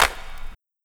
Car Wash Clap4.wav